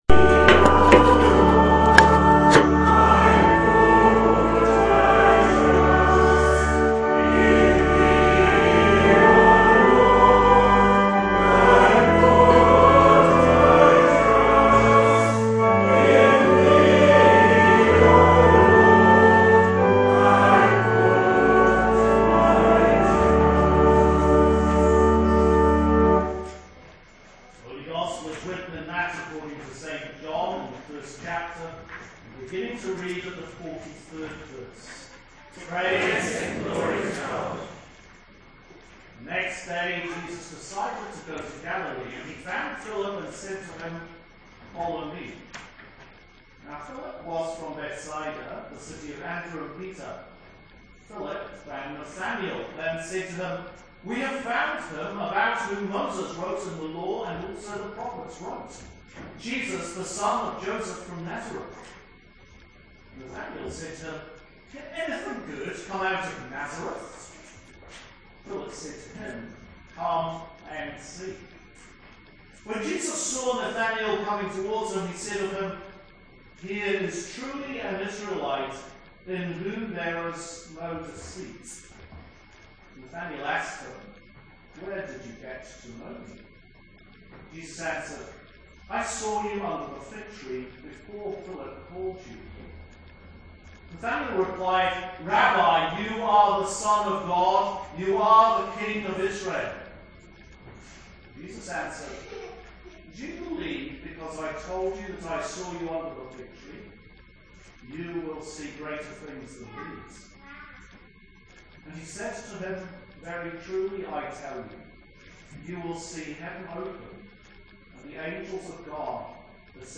Sermon for Lent 2 – Year B Sunday March 1st 2015